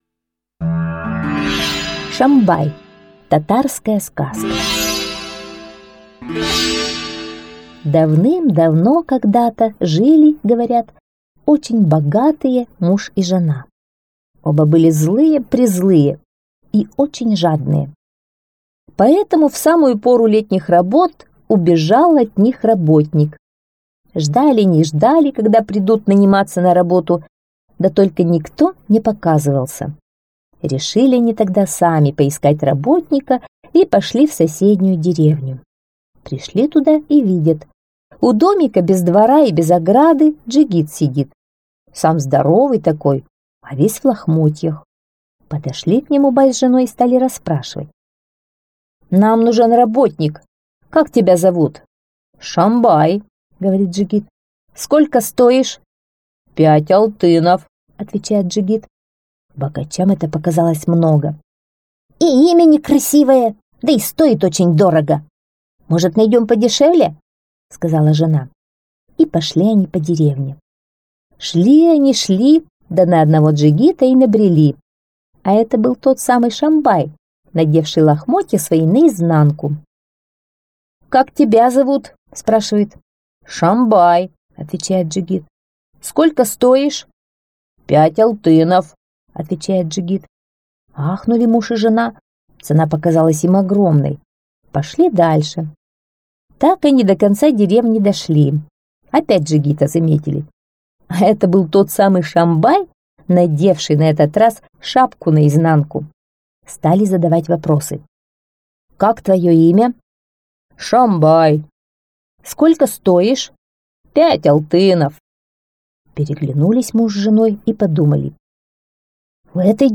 Шомбай - татарская аудиосказка - слушать онлайн